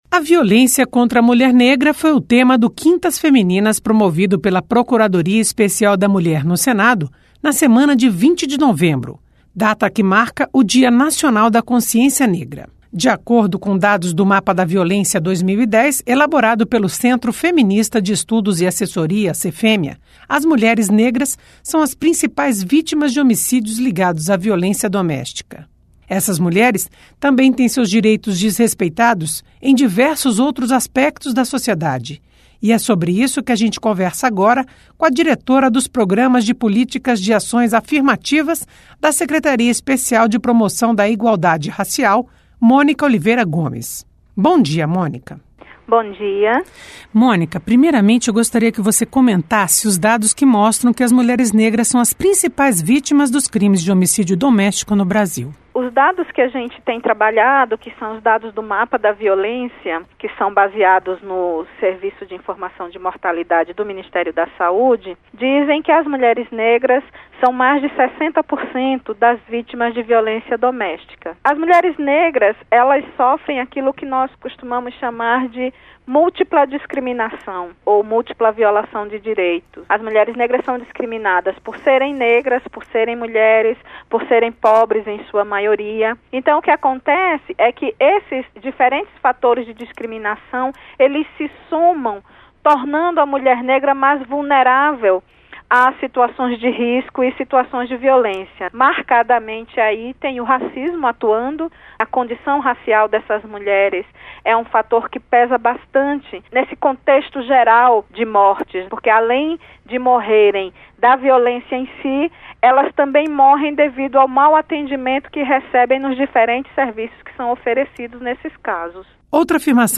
Entrevista: Violência contra a mulher negra